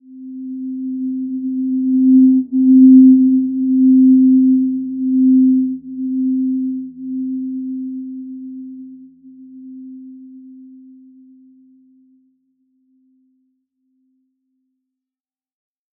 Simple-Glow-C4-p.wav